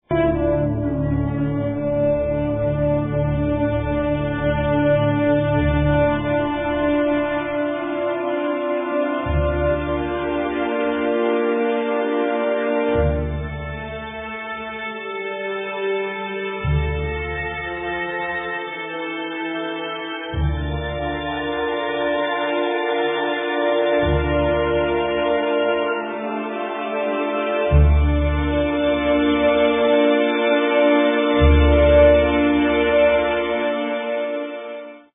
kawalaa reed-flute typical of Egyptian folk music
nayan Arab reed-flute
'uda Middle Eastern short-necked plucked lute